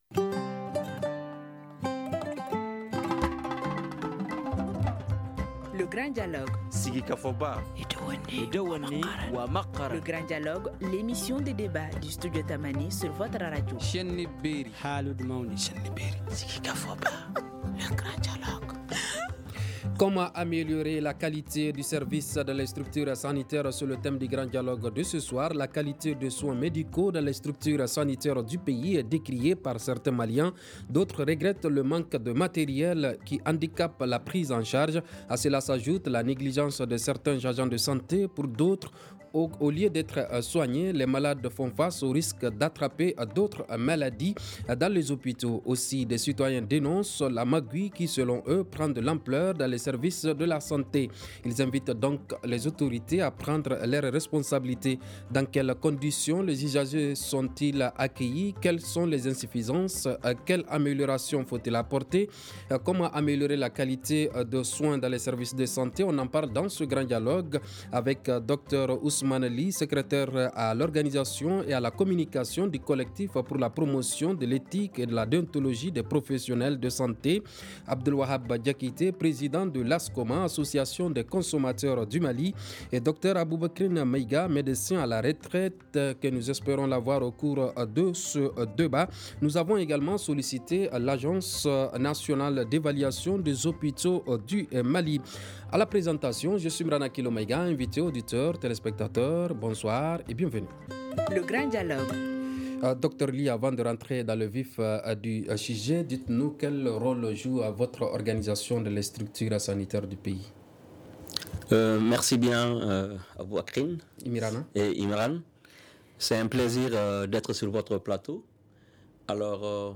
On en parle dans le grand dialogue :